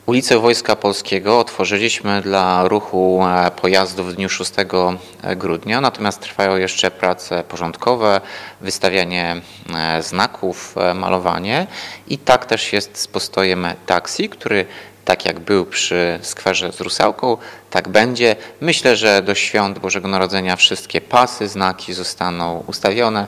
– Postój będzie – uspokaja zainteresowanych prezydent Ełku, Tomasz Andrukiewicz.